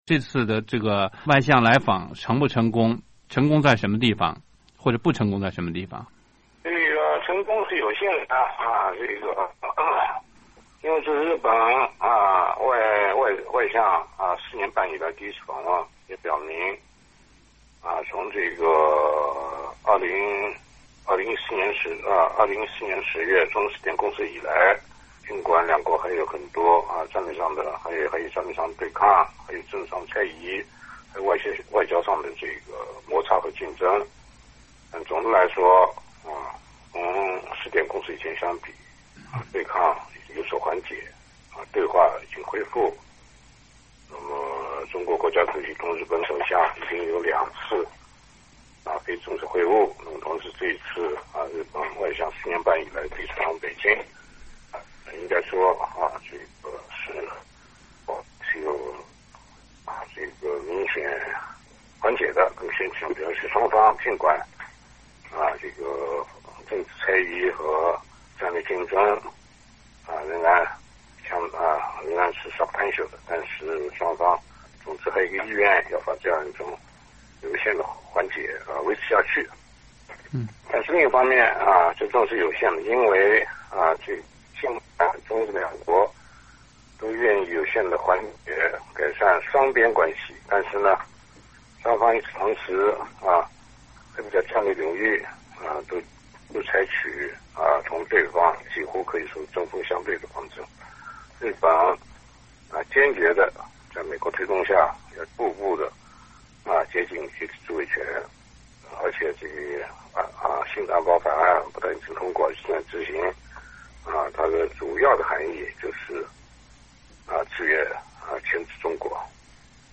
美国之音记者电话专访了时殷弘教授。